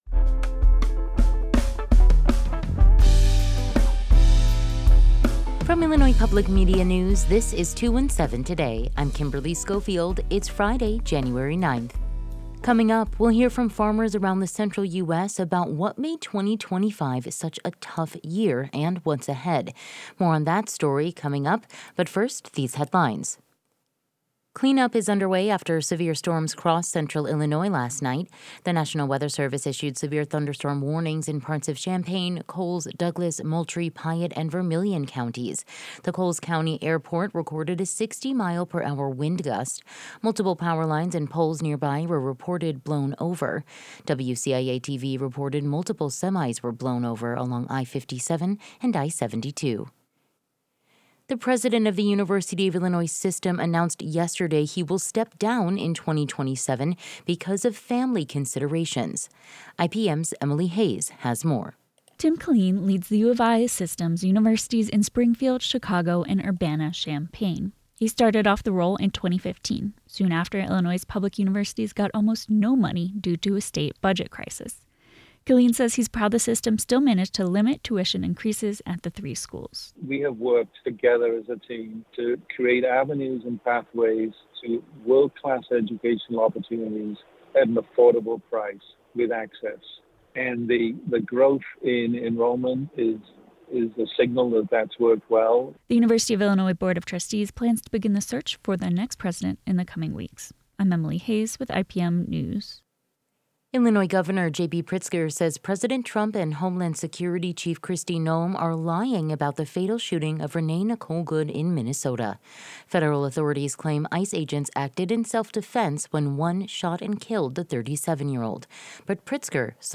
Today’s headlines:
We'll hear from farmers around the central US about what made 2025 such a tough year and what's ahead.